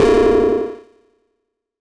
dong.wav